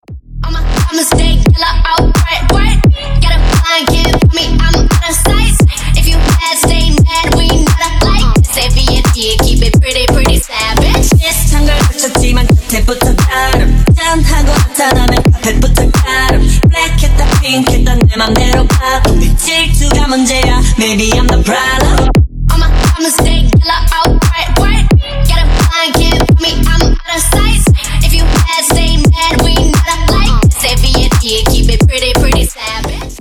Клубный саунд 2024 для трубы
• Песня: Рингтон, нарезка